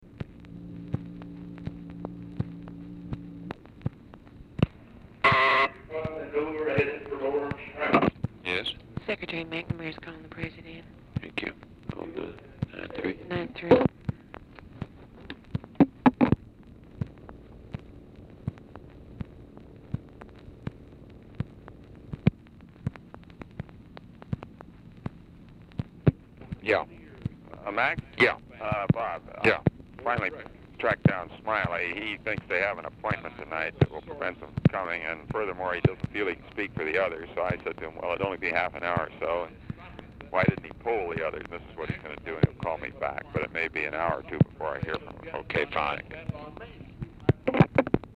Telephone conversation # 2988, sound recording, LBJ and ROBERT MCNAMARA, 4/10/1964, 1:40PM
MCNAMARA CALLS FOR LBJ; MOYERS ANSWERS THE TELEPHONE AND MCNAMARA TALKS TO BUNDY INSTEAD OF LBJ; LBJ IS AUDIBLE IN BACKGROUND
Format Dictation belt